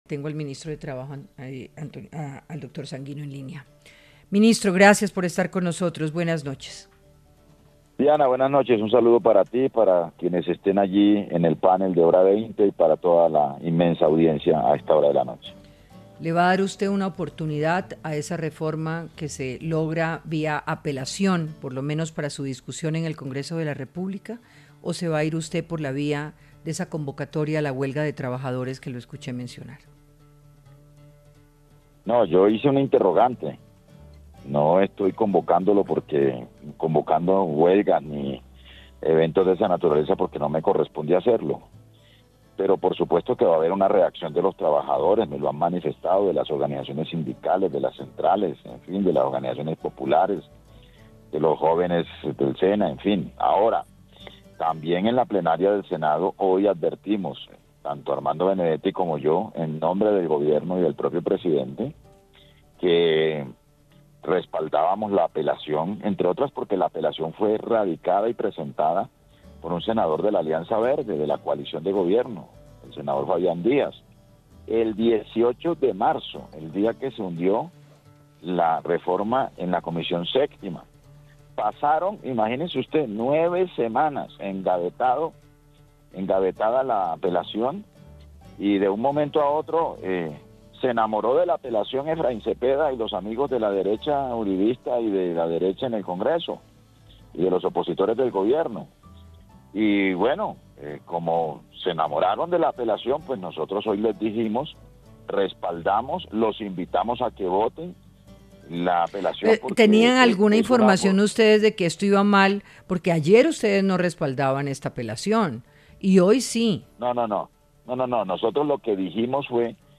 En diálogo con Hora20 de Caracol Radio, el ministro del Trabajo, Antonio Sanguino manifestó que él no está convocando a huelga general, como se ha señalado desde varios medios, “sólo hice un interrogante, a mí no me corresponde hacer ese llamado, pero va a haber reacción de sindicatos, de organizaciones populares y de jóvenes del Sena al hundimiento del llamado a consulta popular”.